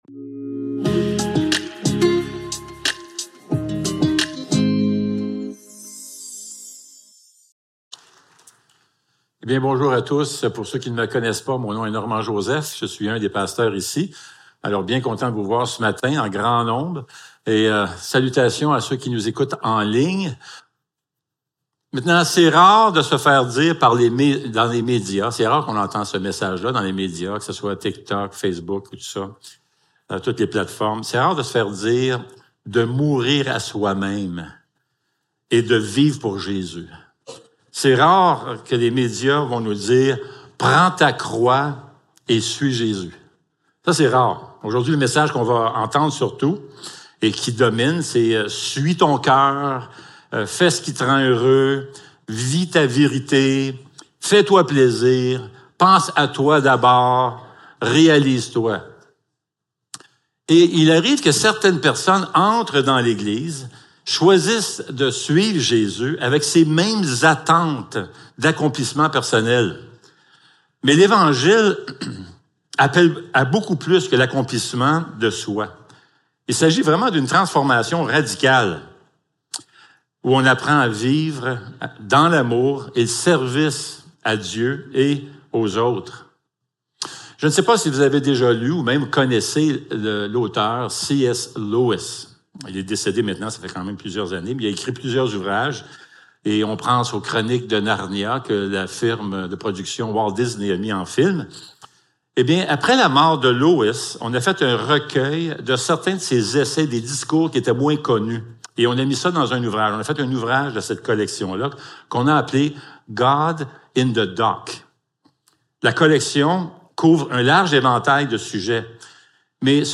Luc 9.57-62 Service Type: Célébration dimanche matin Description